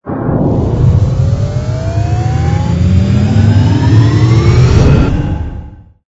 engine_ku_cruise_start.wav